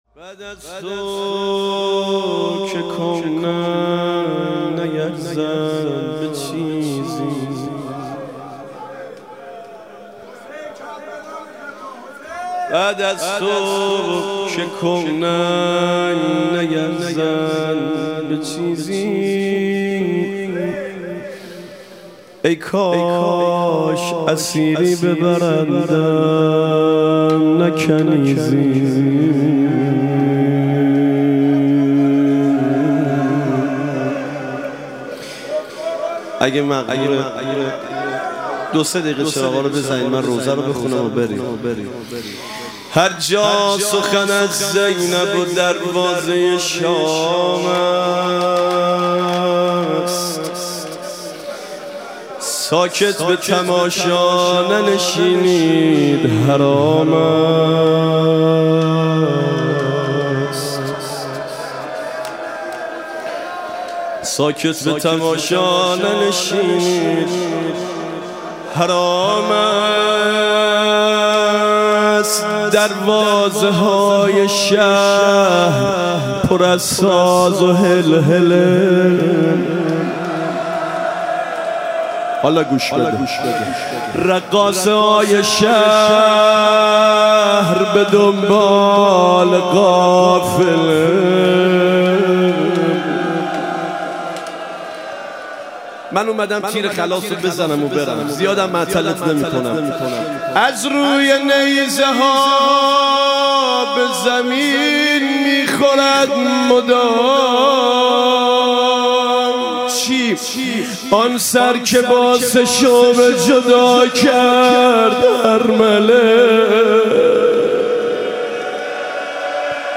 شش امام حسین علیه السلام - روضه